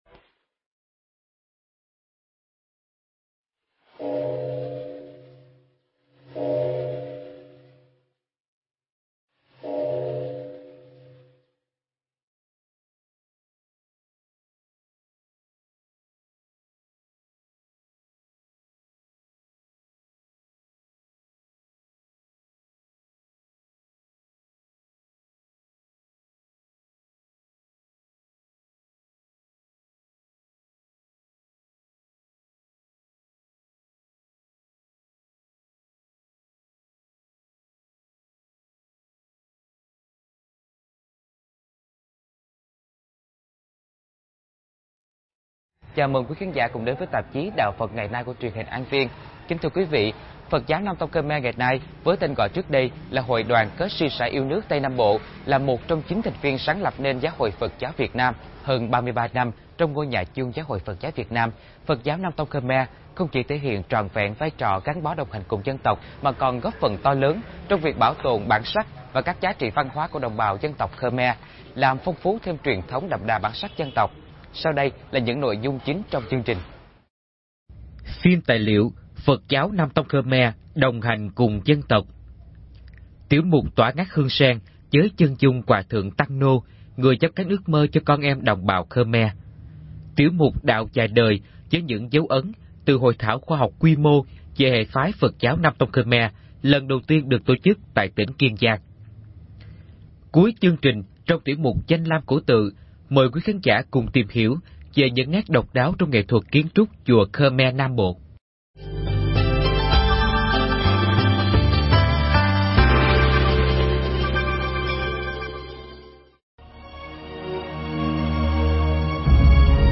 Pháp âm Phật giáo Nam tông Kh’mer đồng hành cùng dân tộc - Thầy Thích Nhật Từ